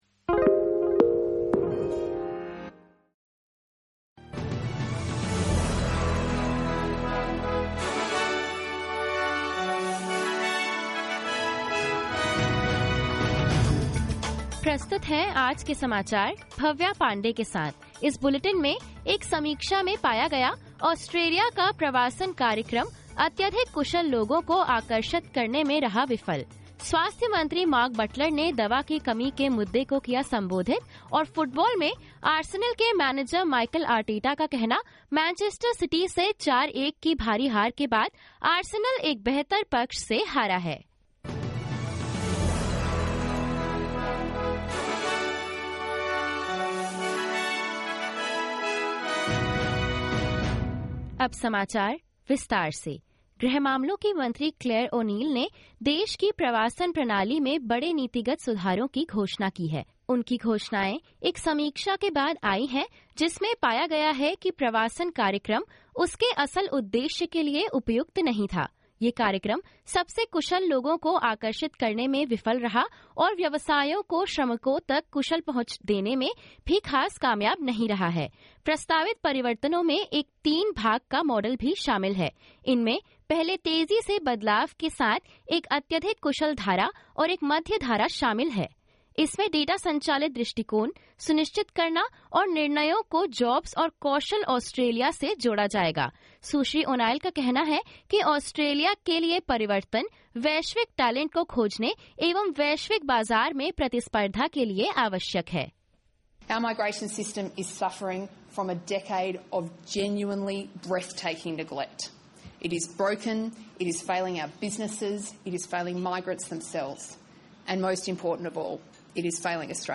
SBS Hindi News 27 April 2023: Government to radically simplify Australia's 'broken' immigration system | SBS Hindi